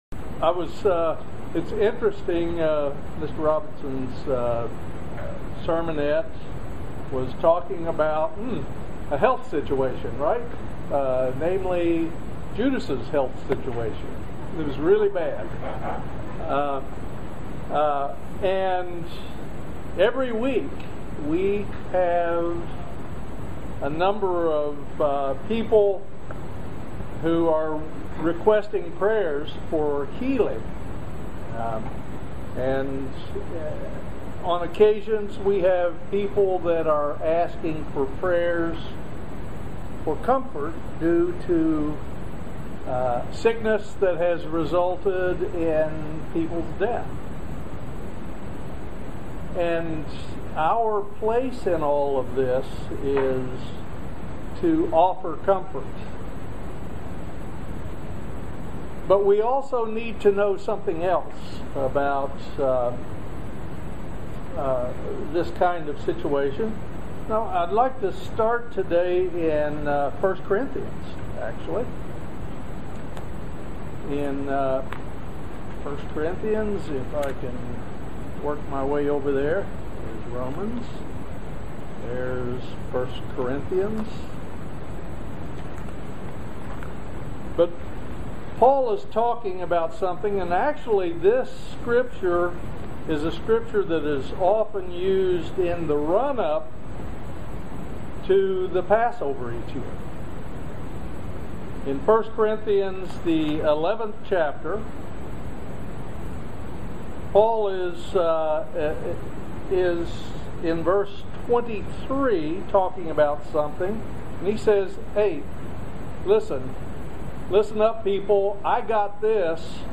Join us for this amazing video sermon on the subject of God's healing. This message goes into detail about What God does and our responsibilities in the process of God's healing. Does God heal everyone?
Given in Lexington, KY